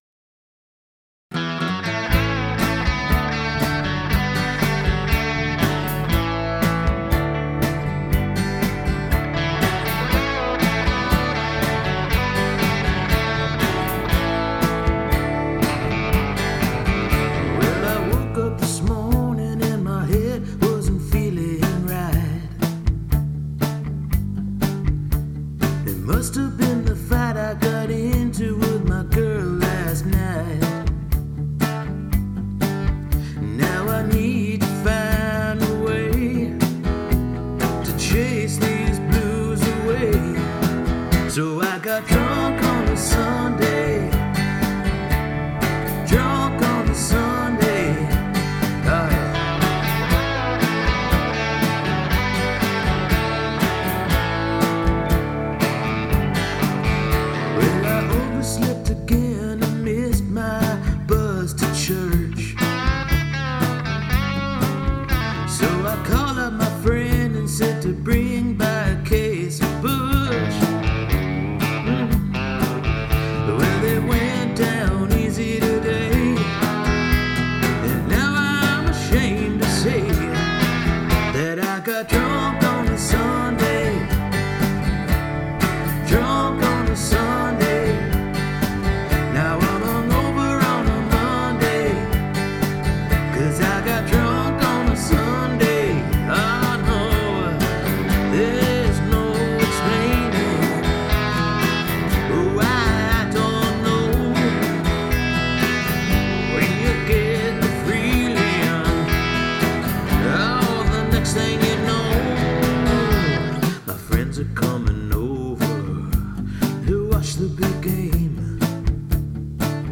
Well, I had one of those dreams a few weeks back and since the melodic hook in the chorus was so memorable to me I recorded a demo of the song when I woke up. The song is called “Drunk on a Sunday” and is unlike any song I would ever write or even enjoy listening to stylistically (modern, cliche ridden pop country).